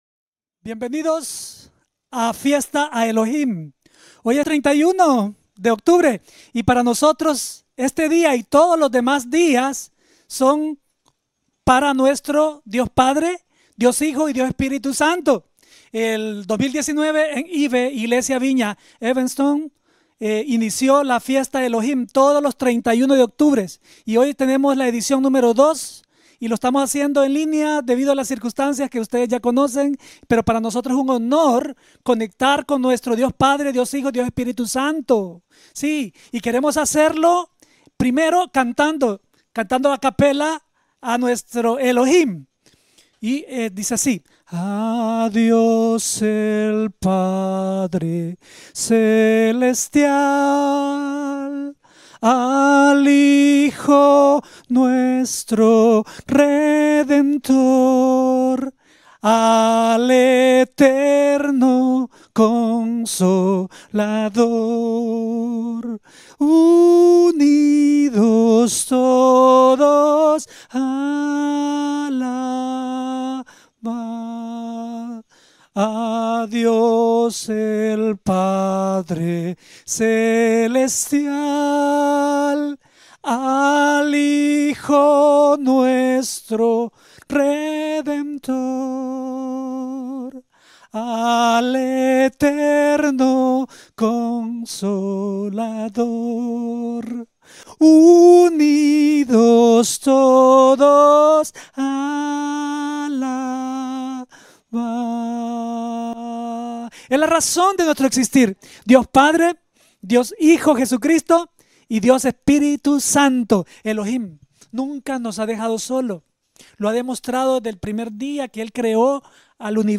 0087 -Prédica en un 31 de Octubre